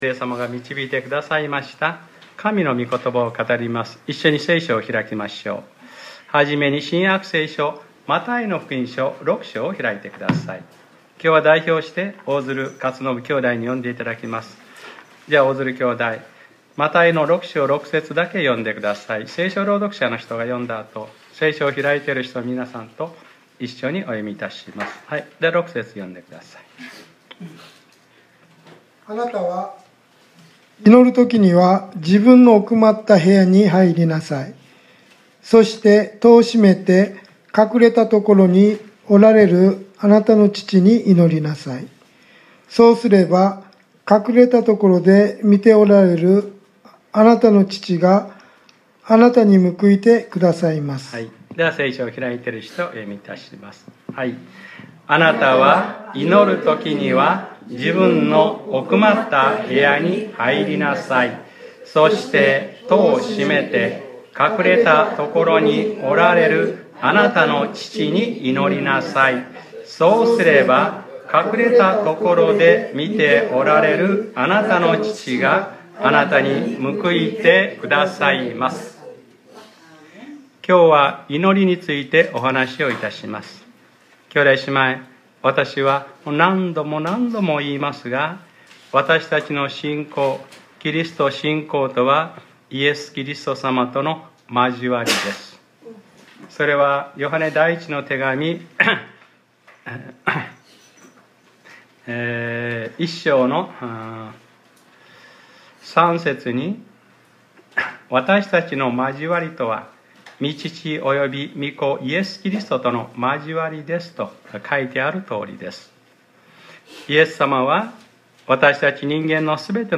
2019年06月02日（日）礼拝説教『シェマーイスラエル』